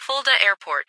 - Added Germany airbases en-US-Studio-O 2025-05-14 10:28:21 +02:00 8.6 KiB Raw Permalink History